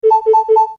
Cuckoo.ogg